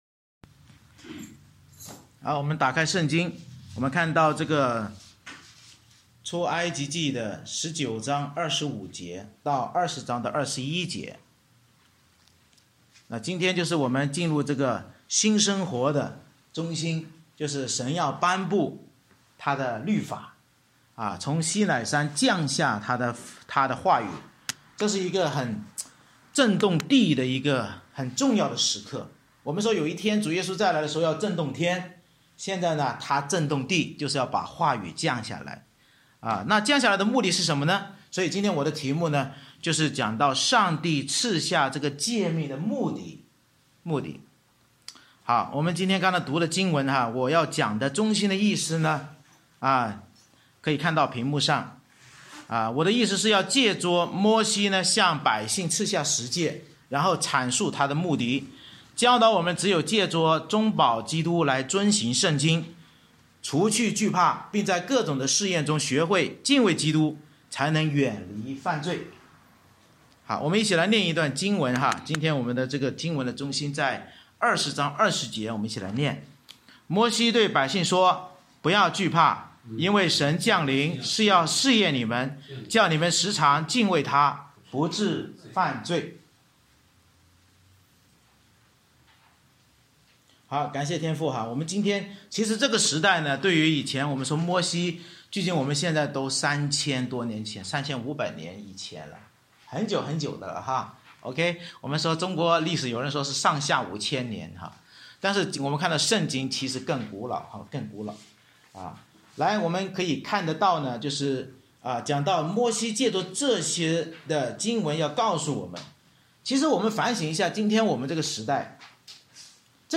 出埃及记19：25-20：21 Service Type: 主日崇拜 Bible Text